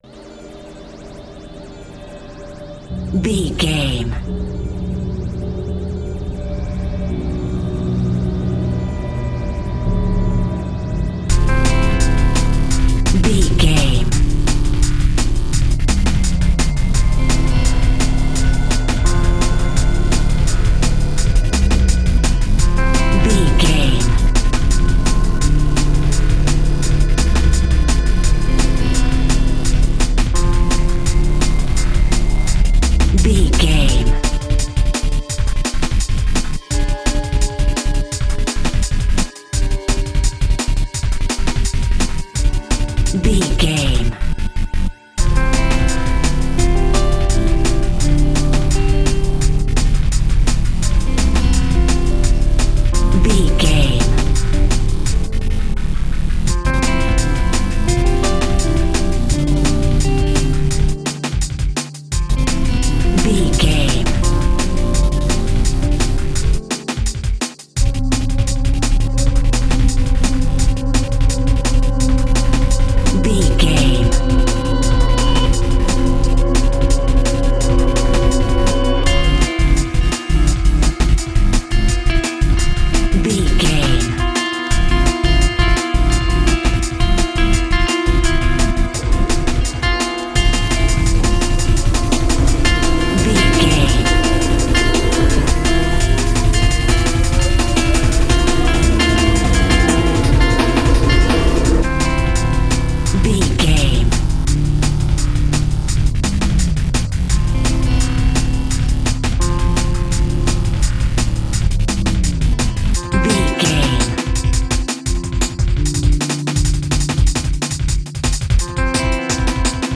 Aeolian/Minor
D
synthesiser
electric guitar
drum machine